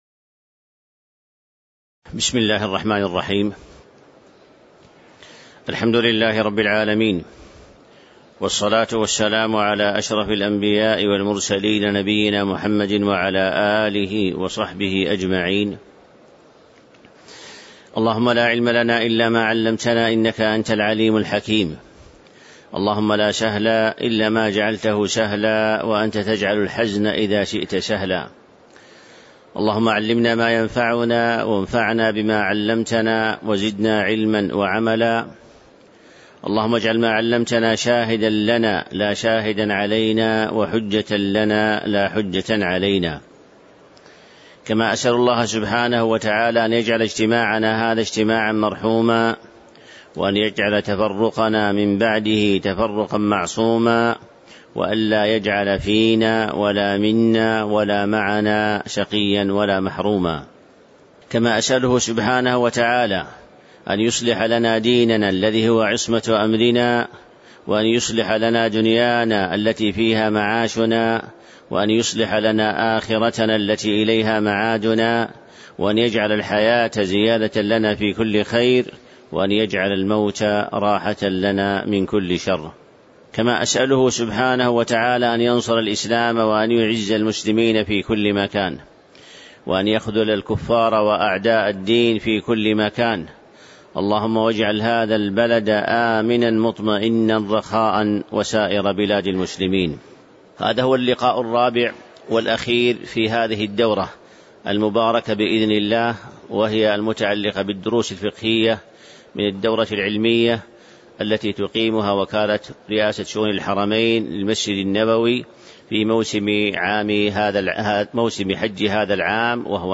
تاريخ النشر ٢٧ ذو القعدة ١٤٤٣ هـ المكان: المسجد النبوي الشيخ